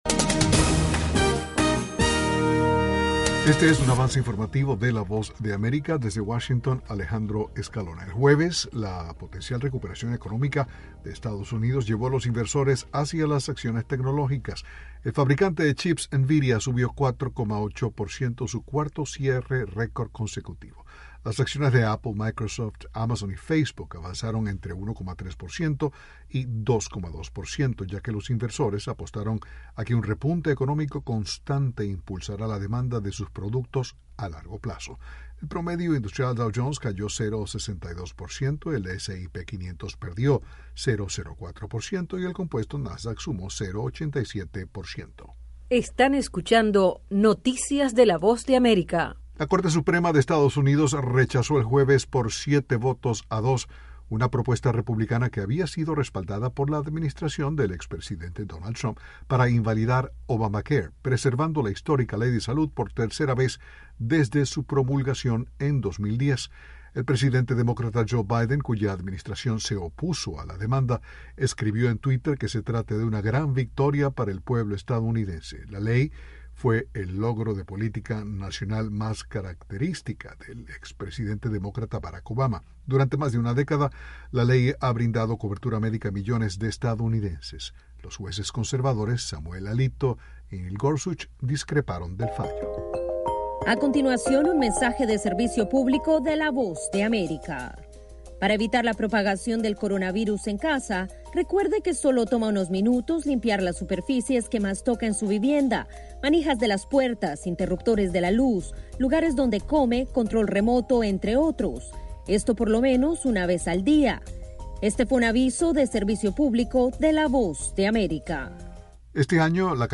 Avance Informativo 6:00pm